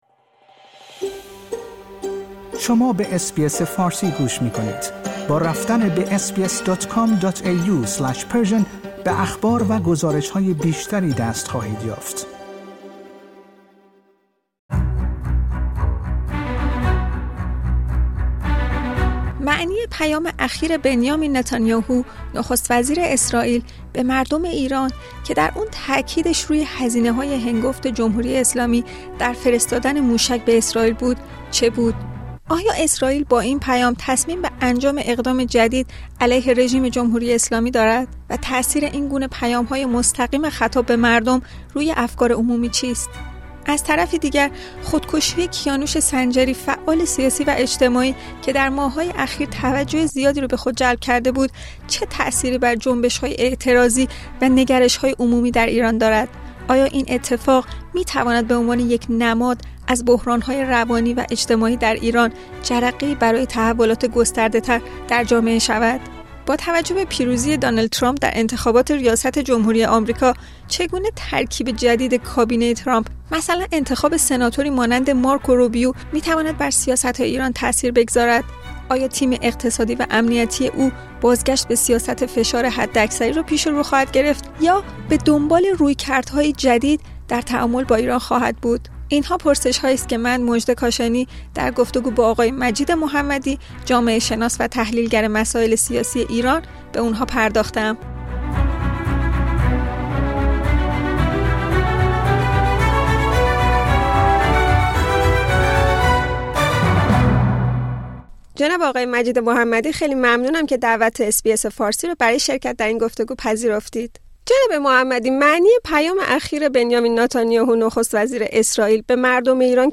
نظرات ارائه شده در این گفتگو نظرات کارشناس مربوطه است و نشانگر دیدگاه های اس بی اس فارسی نیست.